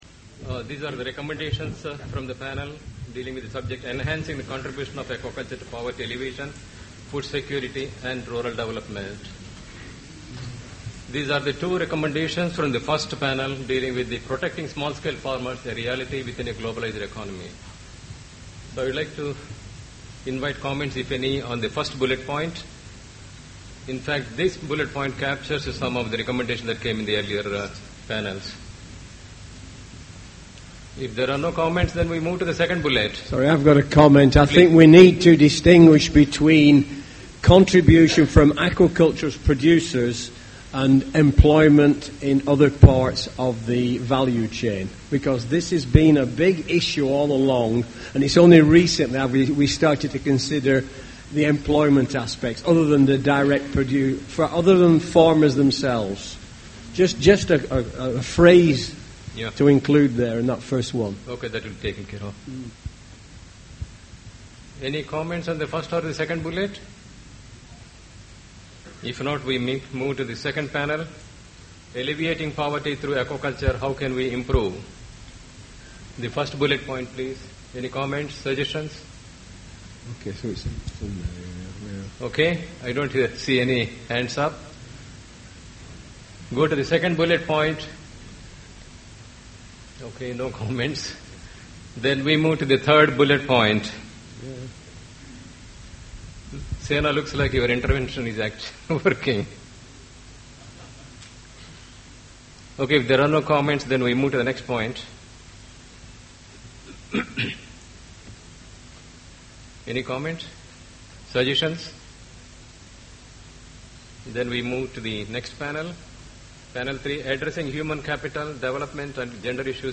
Presentation of the summary, conclusions and recommendations of Thematic Session 6 (Enhancing the contribution of aquaculture to poverty alleviation, food security and rural development).
Related Global Conference on Aquaculture 2010 The conference was organised by FAO, the Thai Department of Fisheries and NACA and held in the Mövenpick Resort and Spa, Phuket, Thailand, 22-25 September.